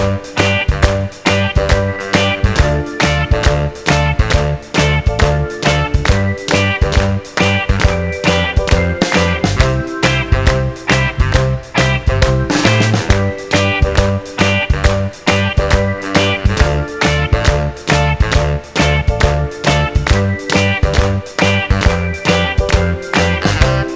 no Backing Vocals Soul / Motown 2:25 Buy £1.50